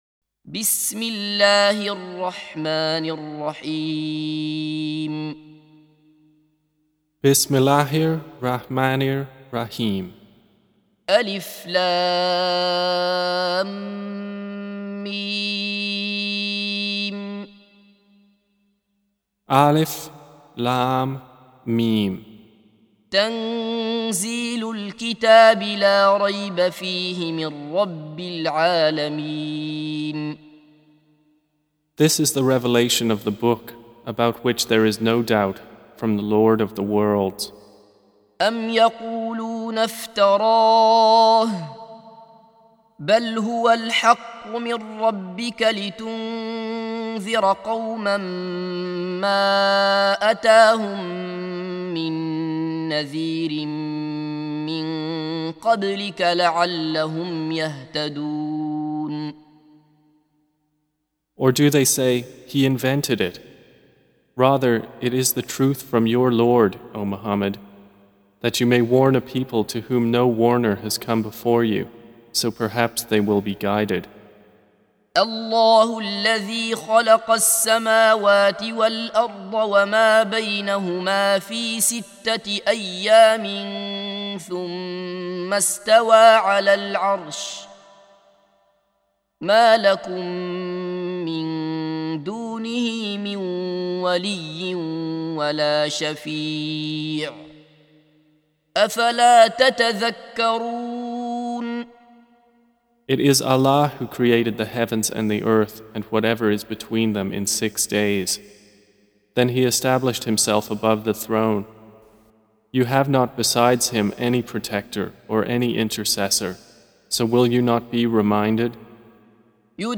Surah Repeating تكرار السورة Download Surah حمّل السورة Reciting Mutarjamah Translation Audio for 32. Surah As�Sajdah سورة السجدة N.B *Surah Includes Al-Basmalah Reciters Sequents تتابع التلاوات Reciters Repeats تكرار التلاوات